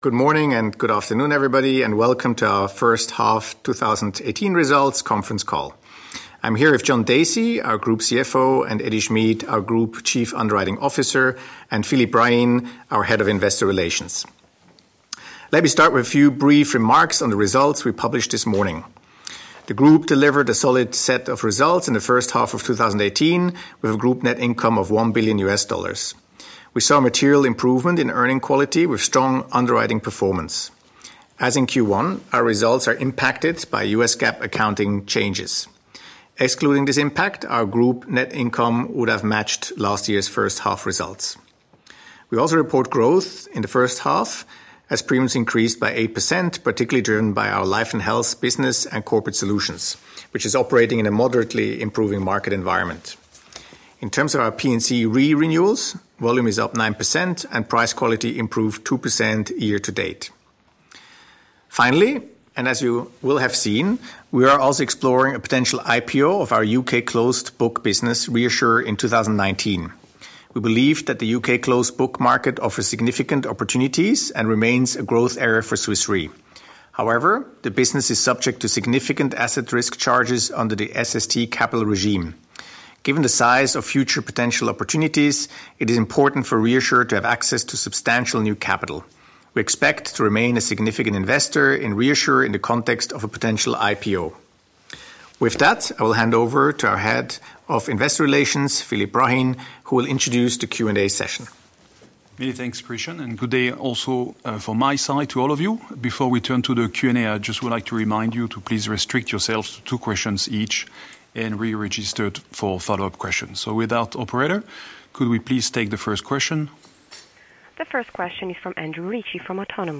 Analysts Conference call recording
2018_hy_qa_audio.mp3